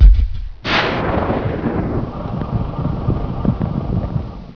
1 channel
MISSLE.WAV